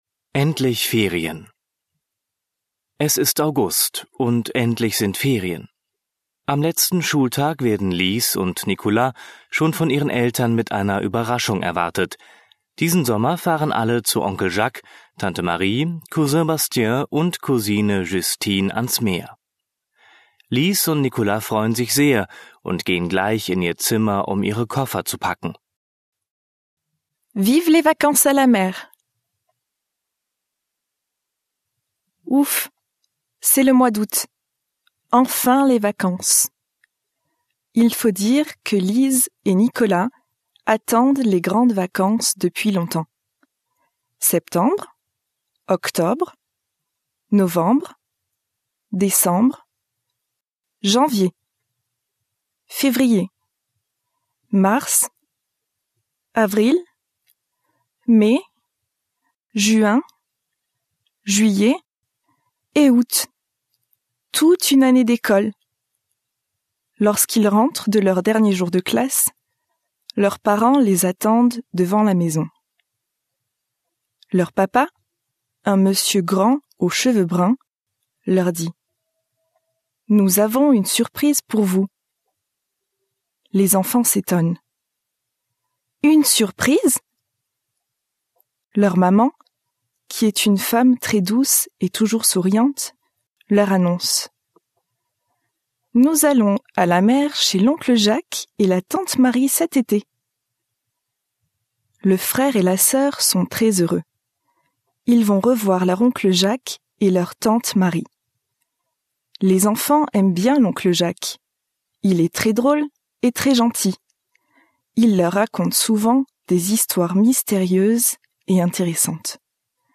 Vor jeder französischen Geschichte ist eine kurze Einführung auf Deutsch gegeben, die den Inhalt der Geschichte kurz zusammenfasst. Alle Geschichten sind von Muttersprachlern gesprochen.